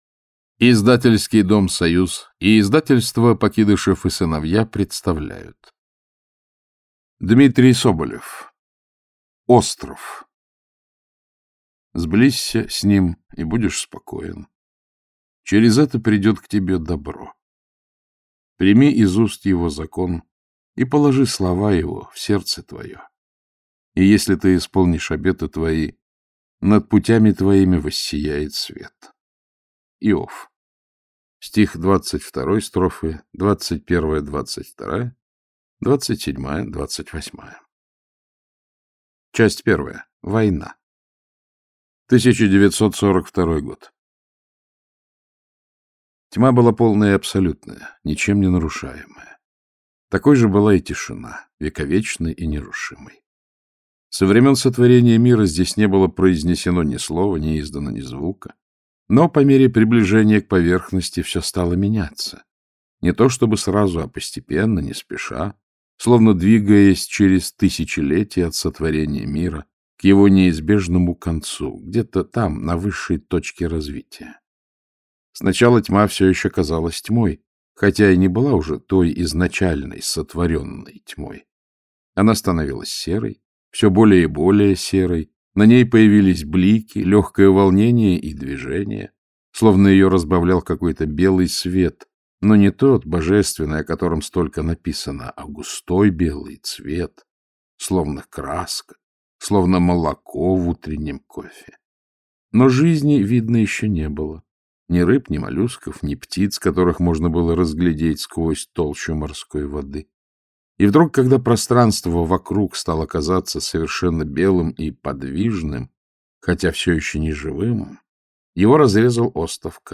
Aудиокнига Остров Автор Дмитрий Соболев Читает аудиокнигу Александр Клюквин.